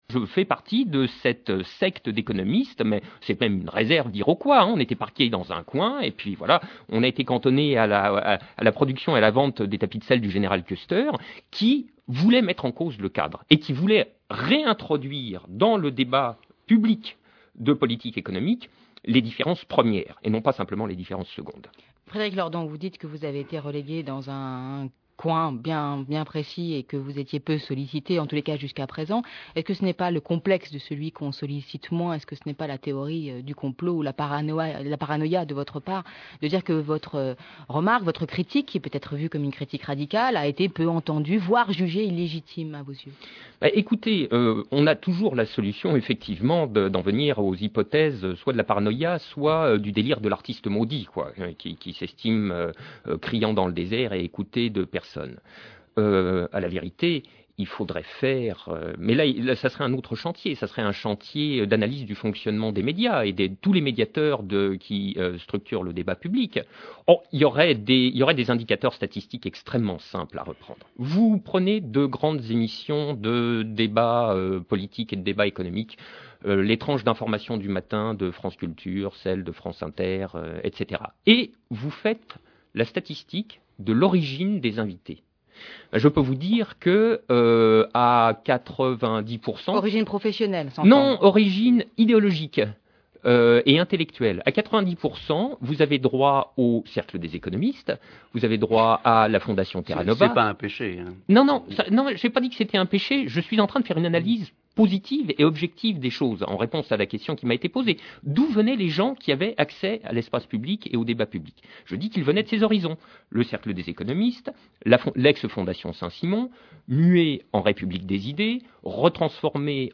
Le 3 novembre 2008, Frédéric Lordon [2] était l’invité du jour.